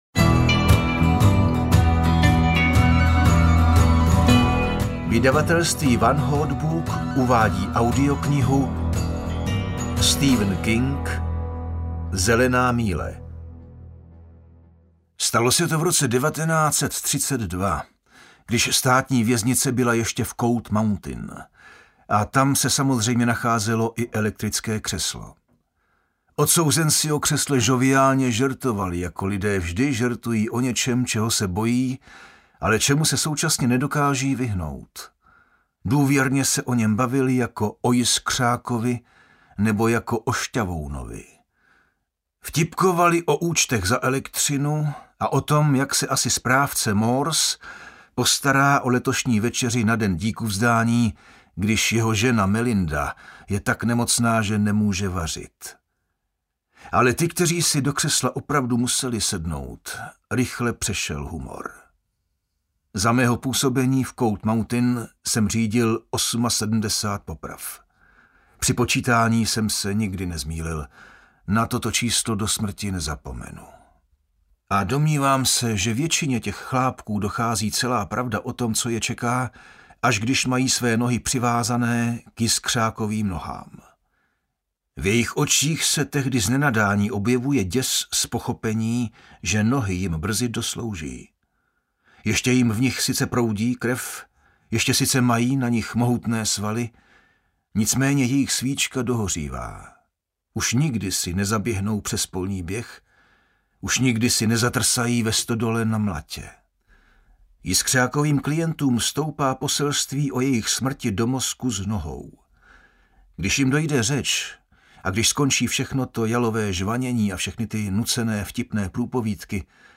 Zelená míle audiokniha
Ukázka z knihy